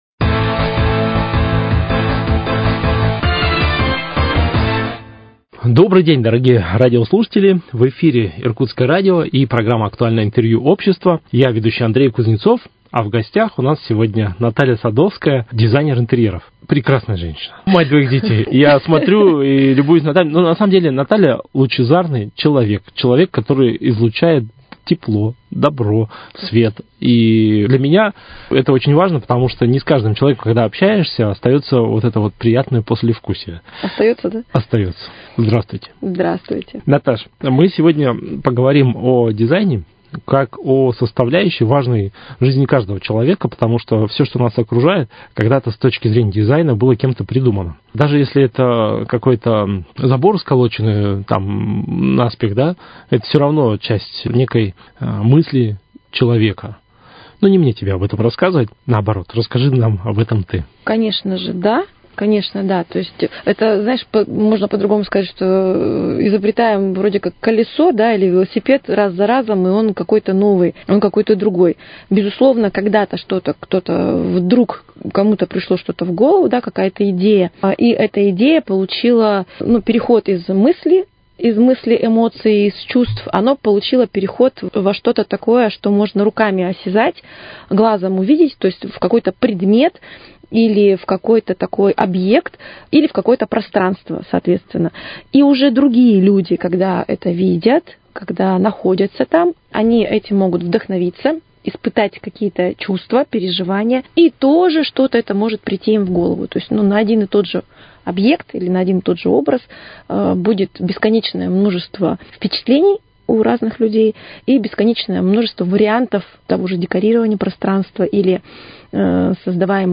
Актуальное интервью